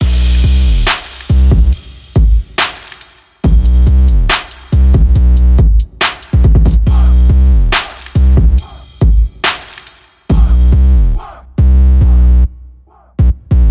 Tag: 140 bpm Trap Loops Drum Loops 2.31 MB wav Key : B FL Studio